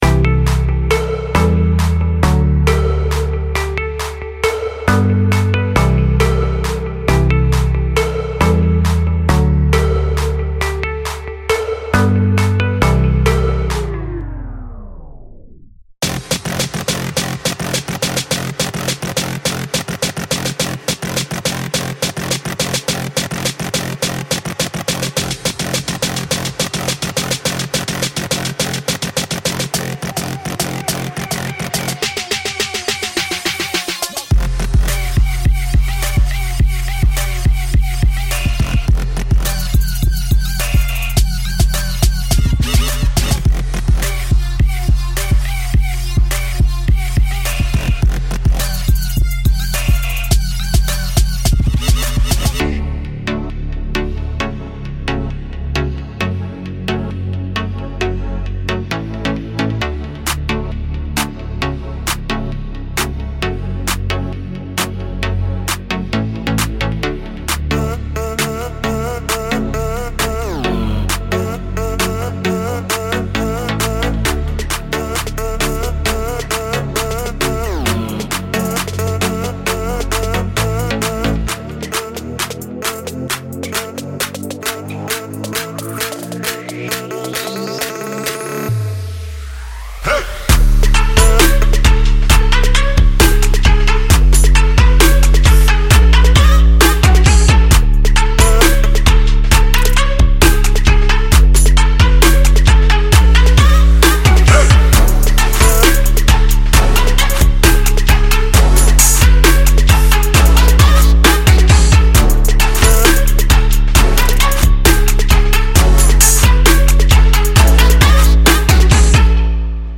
从疯狂的低音提琴，旋律弹拨，颤音垫，海豚声音的重制到您所听过的最酷的吉他弹奏合成器之一，此组合包含了一切。
• 85个鼓采样